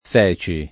f$kS]: latinismo dòtto, sostituito nel ’900 come term. med. a fecce, pl. di feccia, voce pop. d’uguale origine — forte tendenza recente all’-e- chiusa, per attraz. di feci pass. rem. di fare